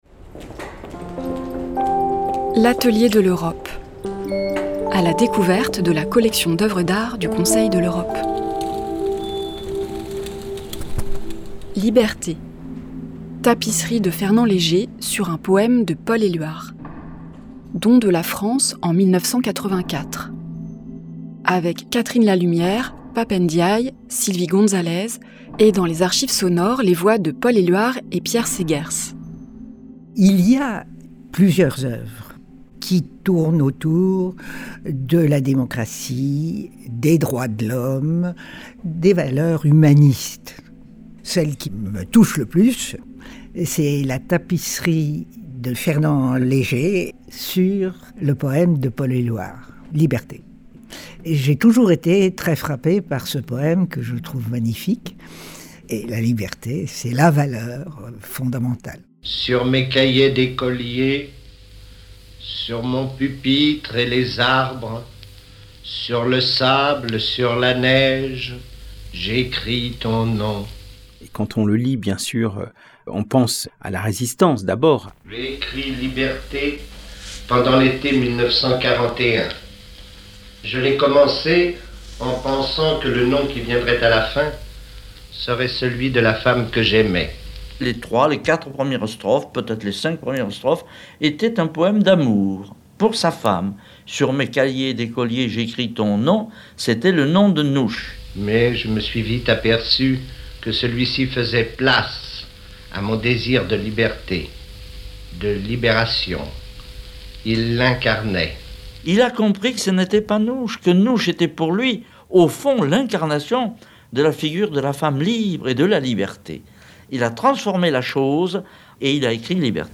Catherine Lalumière, ancienne Secrétaire Générale du Conseil de l’Europe (1989-1994)
Pap Ndiaye, Ambassadeur, Représentant permanent de la France auprès du Conseil de l’Europe
Archives sonores : Paul Éluard et Pierre Seghers